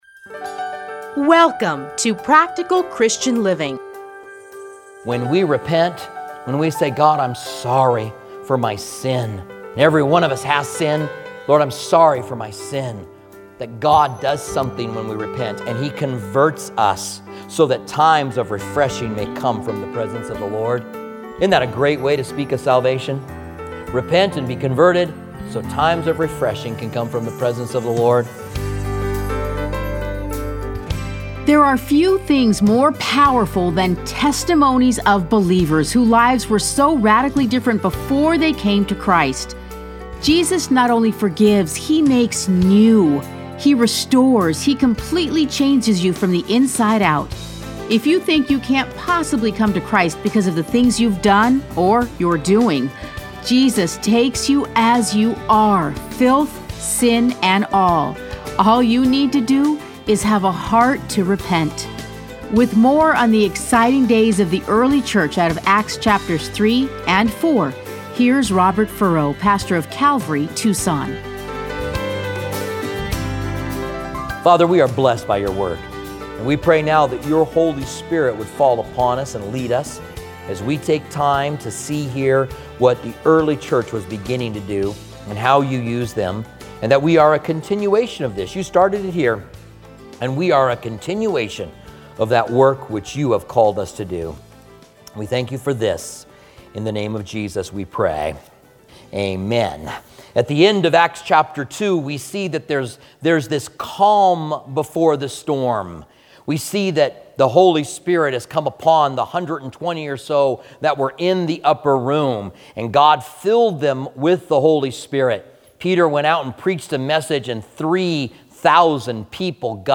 Listen to a teaching from Acts 3-4.